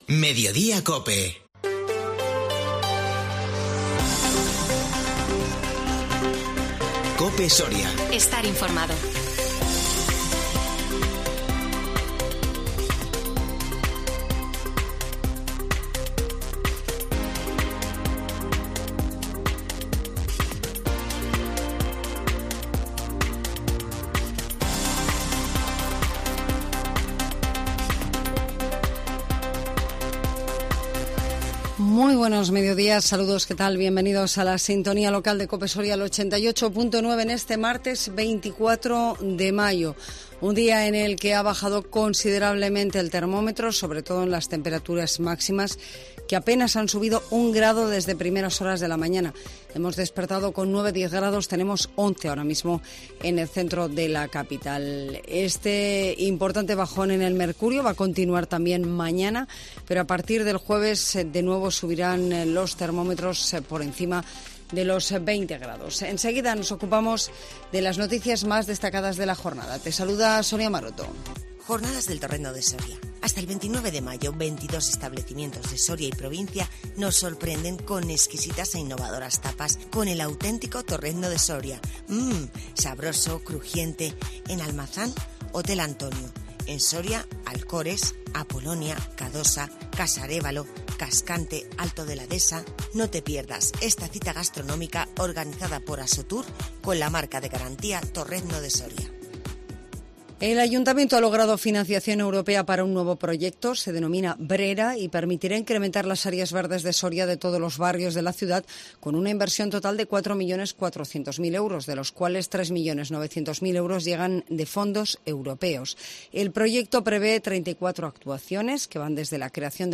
INFORMATIVO MEDIODÍA COPE SORIA 24 MAYO 2022